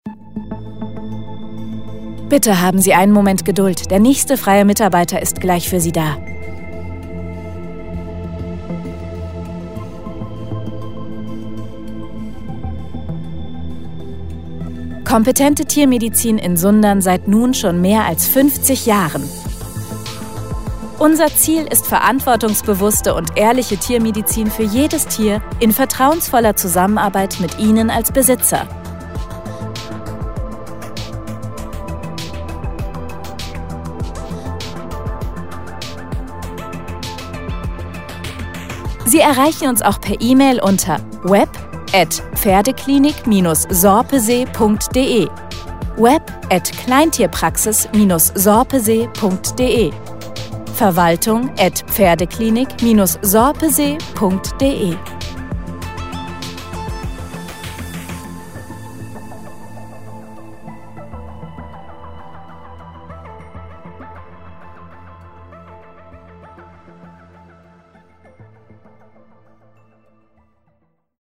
Warteschleife
Kleintierpraxis-Schlaufe.mp3